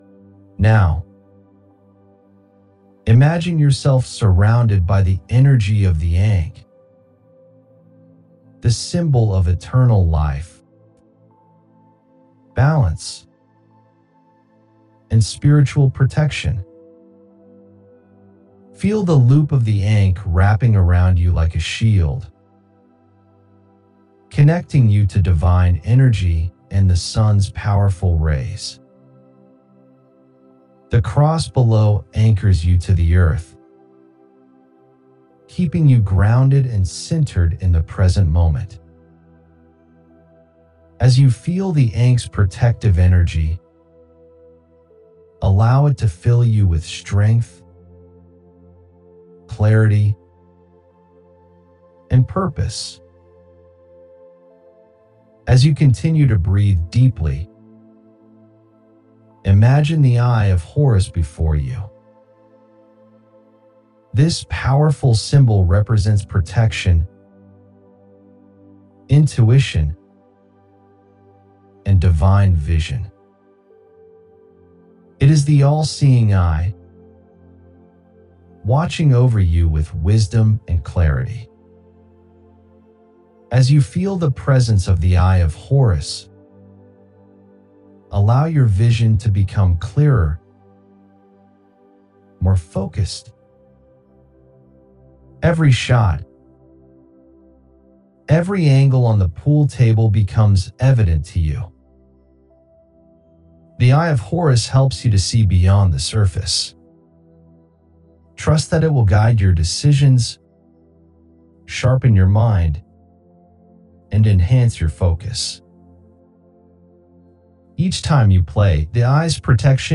eightball_demo.mp3 $29.95 Recording Extract Please note that recordings with Talismans are sent within Australia only